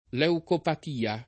leucopatia [ l H ukopat & a ]